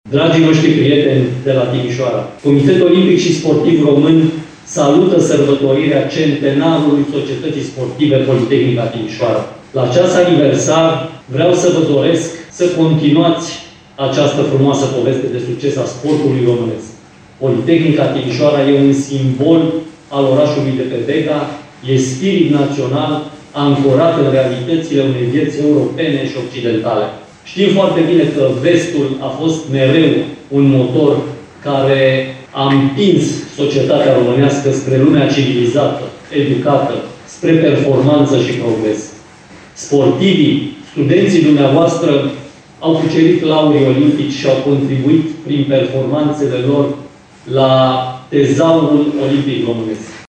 Biblioteca Universității Politehnica Timișoara a reunit astăzi câteva dintre gloriile lui Poli, la aniversarea de 100 de ani a clubului.
Președintele COSR, Mihai Covaliu, a fost prezent în direct la festivitatea de la Timișoara prin intermediul unei platforme sociale
Printre cei ce au transmis gândul lor la împlinirea a 100 de ani s-a aflat și președintele Comitetului Olimpic și Sportiv Român (COSR), Mihai Covaliu:
Covaliu-la-centenar-Poli.mp3